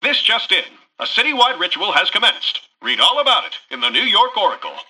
Newscaster_headline_09.mp3